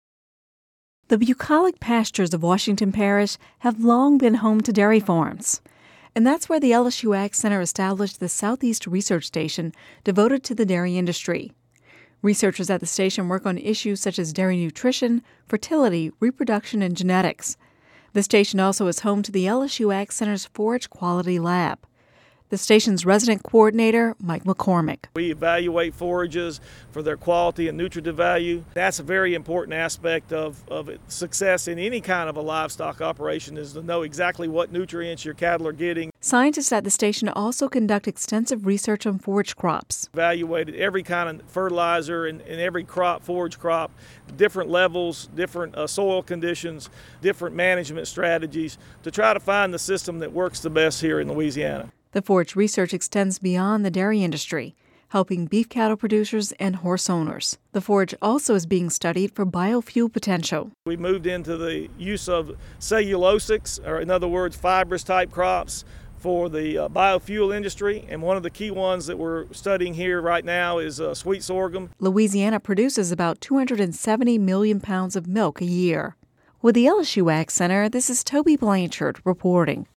(Radio News 01/24/11) Scientists at the LSU AgCenter's Southeast Research Station work on issues such as dairy nutrition, fertility, reproduction and genetics. The station also is home to the AgCenter’s forage quality lab.